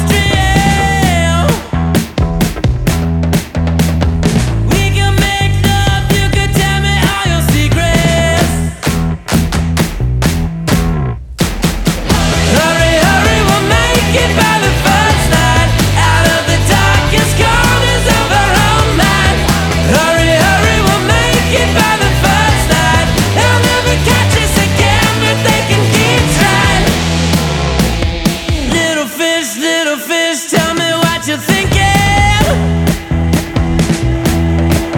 Жанр: Поп / Рок